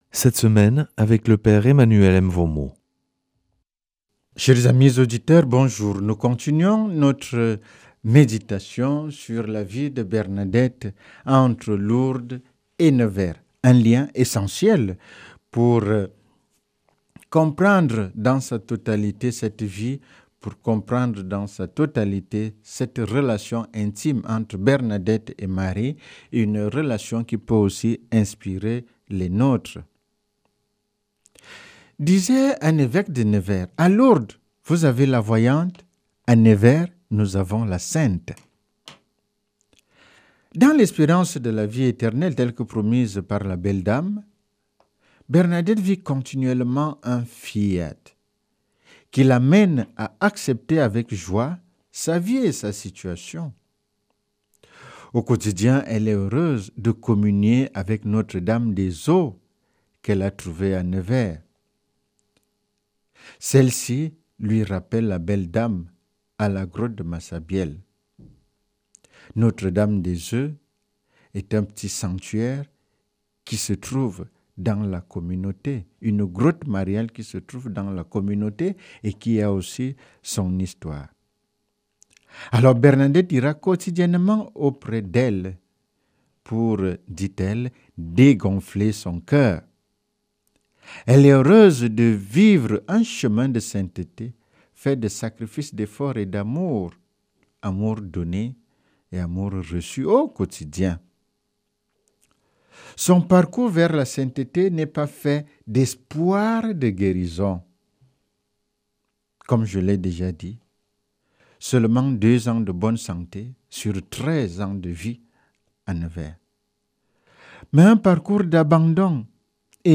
mercredi 27 août 2025 Enseignement Marial Durée 10 min
Une émission présentée par